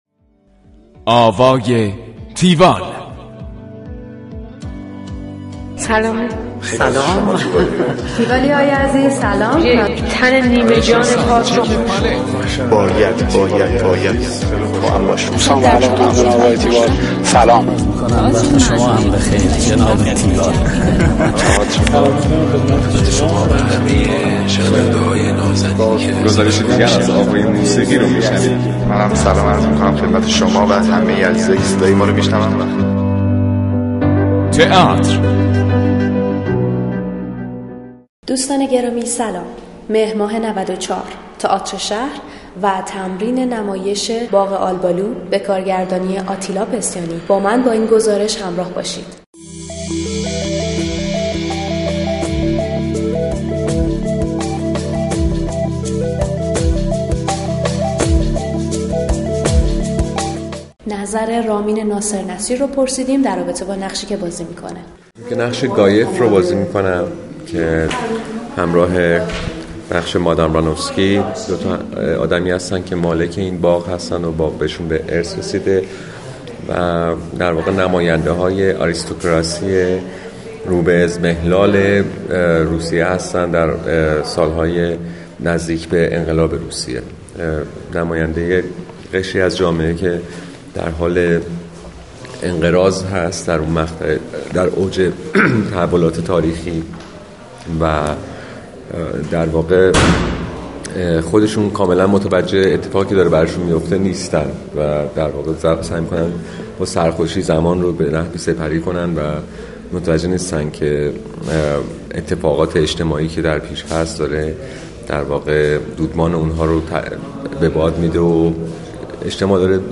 گزارش آوای تیوال از نمایش باغ آلبالو
گفتگو با